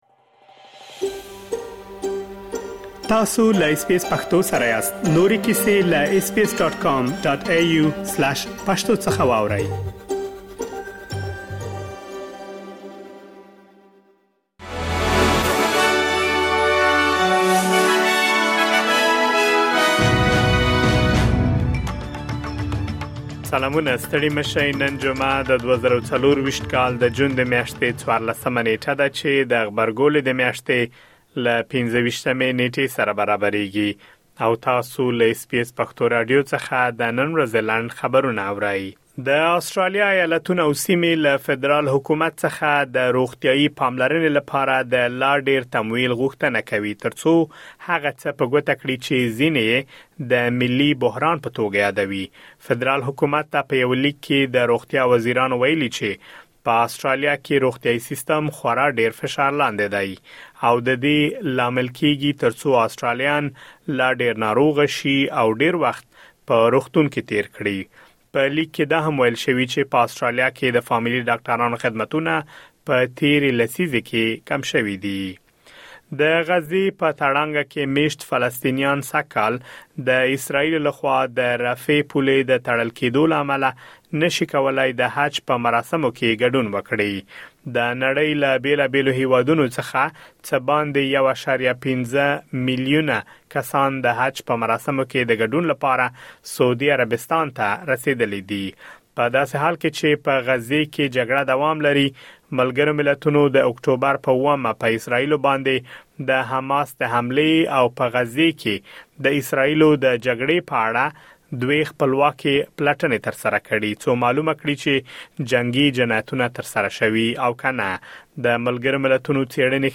د اس بي اس پښتو د نن ورځې لنډ خبرونه|۱۴ جون ۲۰۲۴